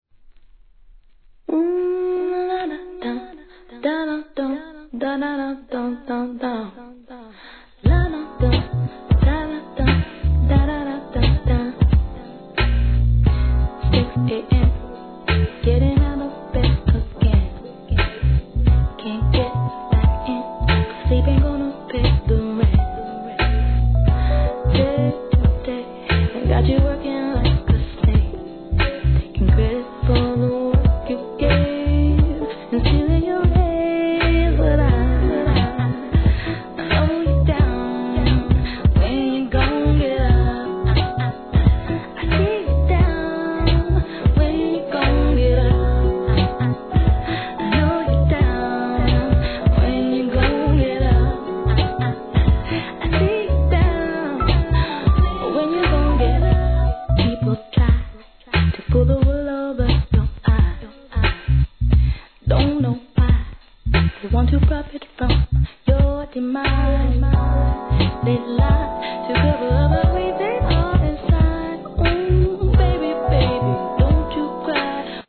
HIP HOP/R&B
洒落オツSOUNDにはまります。